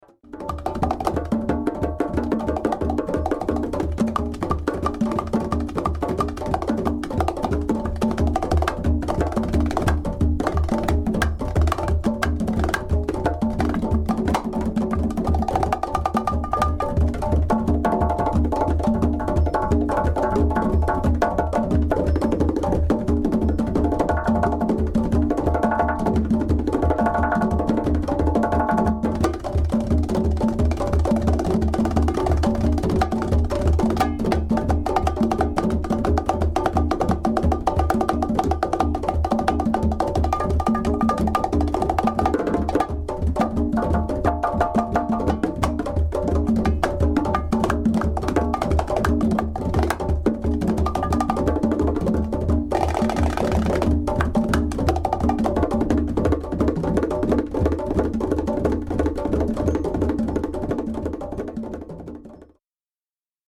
Conga & Bongo solo